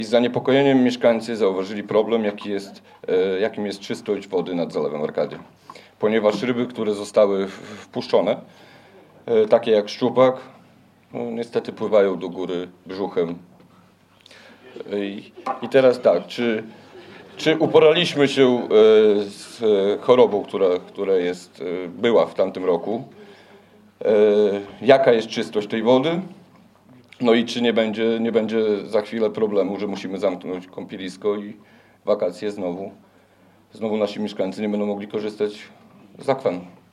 O przyszłość zalewu Arkadia w Suwałkach pytał na ostatniej sesji Rady Miejskiej Kamil Lauryn, randy klubu „Łącza nas Suwałki”. Chodzi o to, że wpuszczone kilka miesięcy temu do akwenu szczupaki zaczęły zdychać.